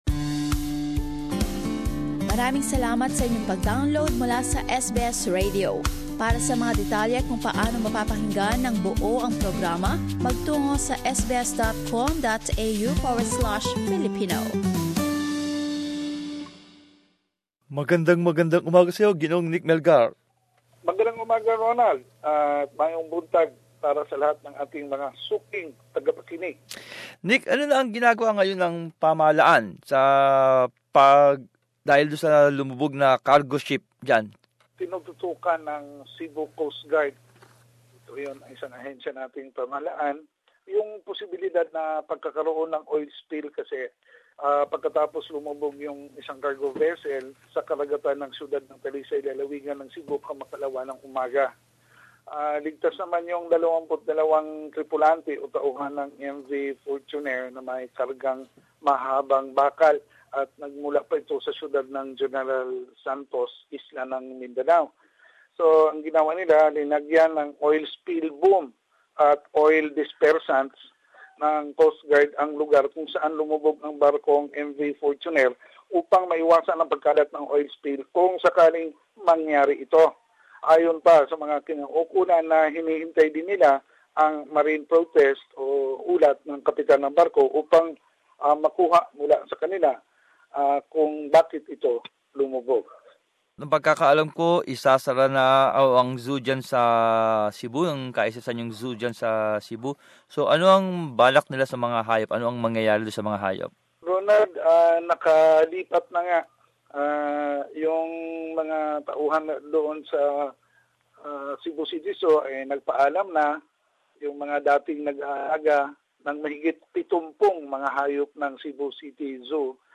Balitang Bisaya.